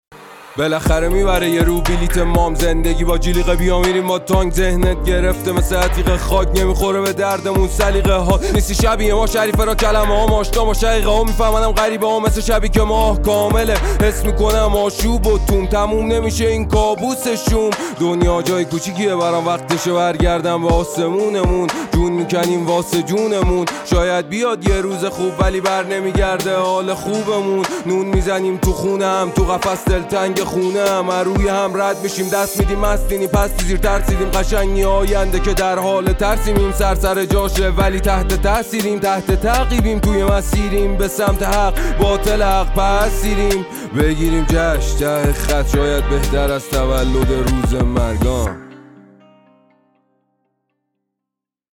ترانه محلی جدید